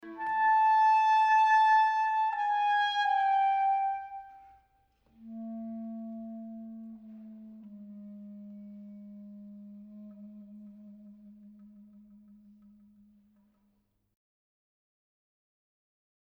Drake Mabry, another American composer, adopts the use of quarter-tones for several musical purposes in his Street Cries (1983) for solo clarinet.
In this example, the repetition sounds much darker because of its quarter-tones, lower dynamic level, and shift to the lower register.